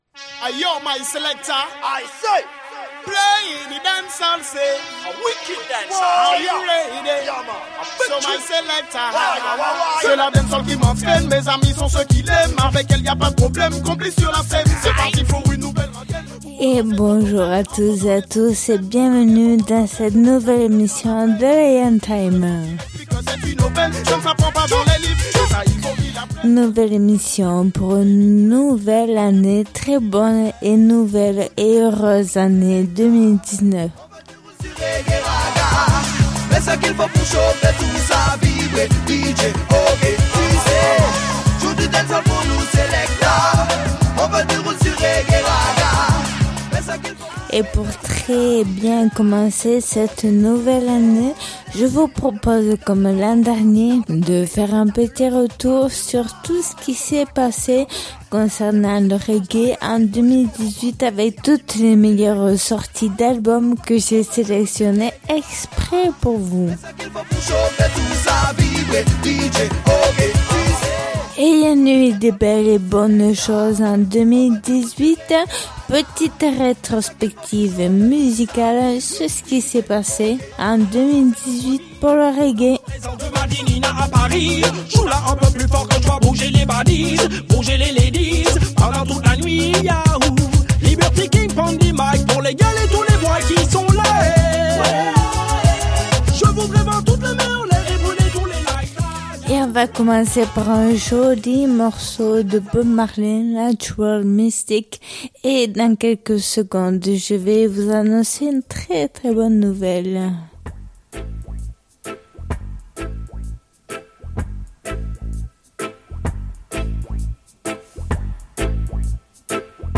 Émission qui invite au voyage et à la découverte du roots, du reggae et du dancehall.